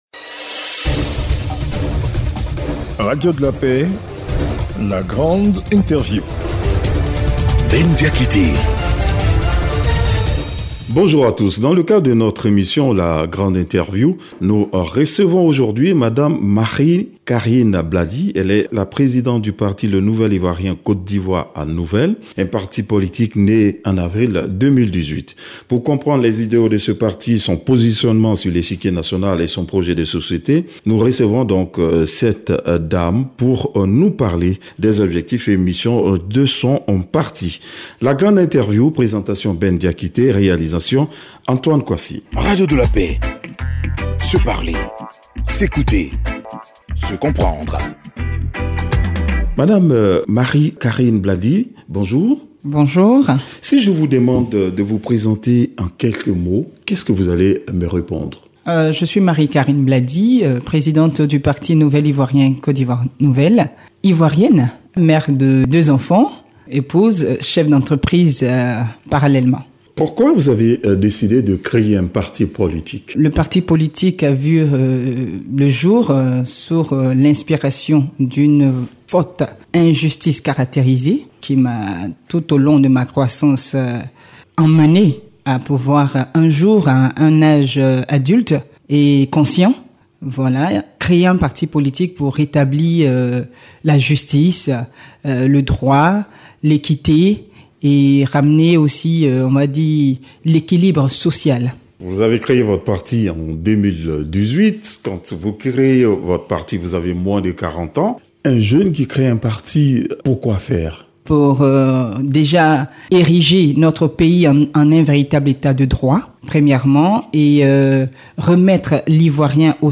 La Grande Interview du 29 avril 2023 - Site Officiel de Radio de la Paix